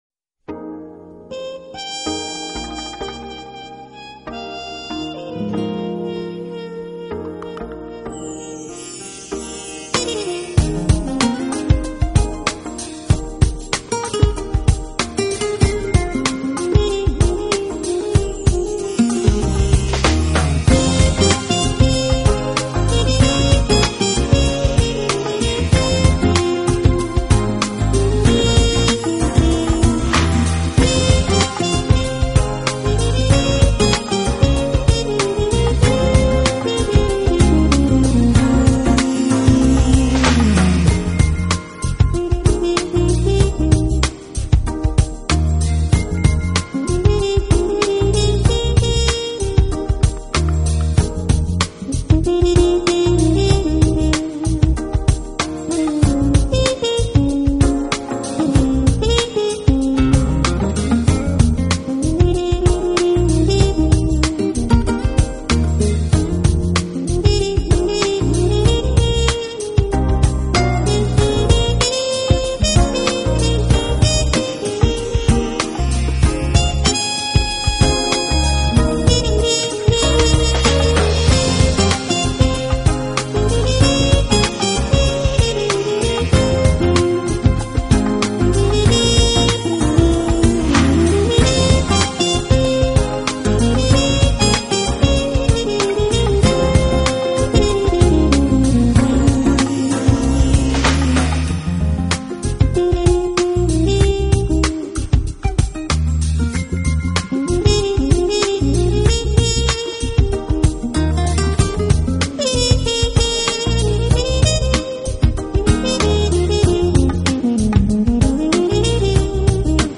专辑类型：Smooth Jazz
他的吹奏总有绵延的泛音